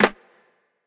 Medicated Snare 18.wav